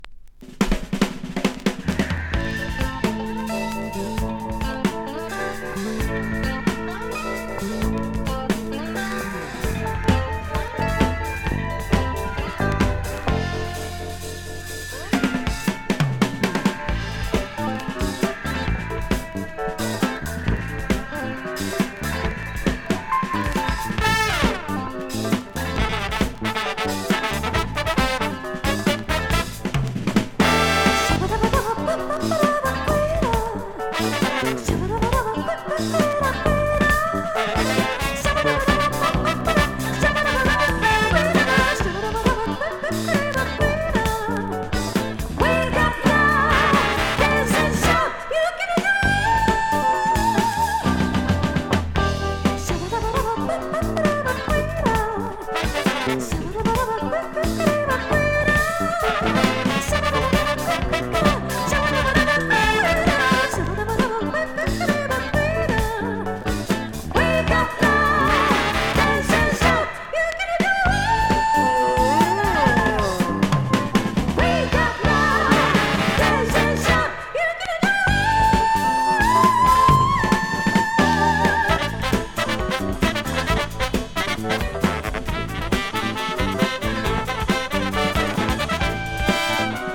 ファンク歌謡！
SSW / FOLK# CITY POP / AOR# 和モノ
スムーズすぎない、というかむしろ拍にひっかかるぎこちない風の言葉が、逆に心に残ります。